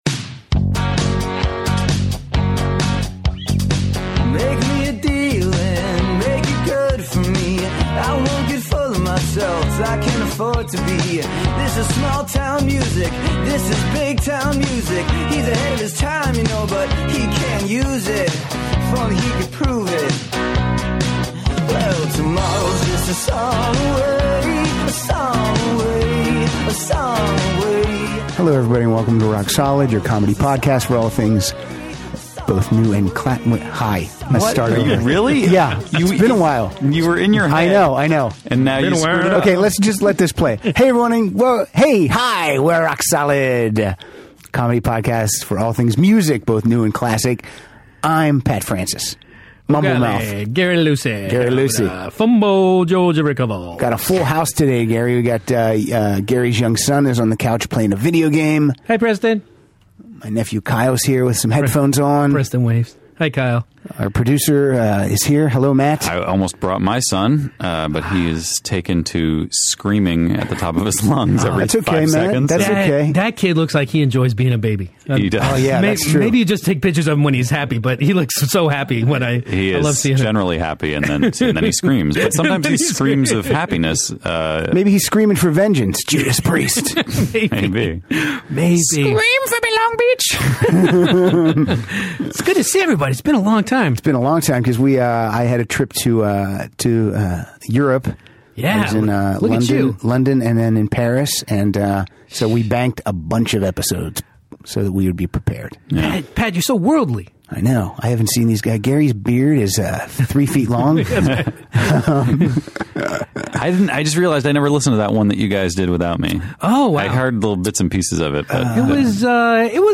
play some of their favorite songs with "smile" or "train" in the title.